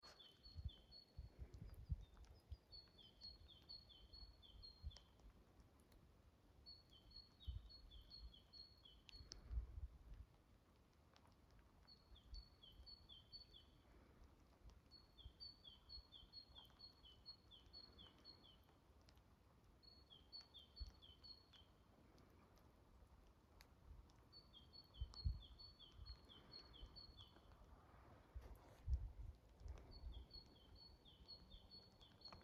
Coal Tit, Periparus ater
StatusVoice, calls heard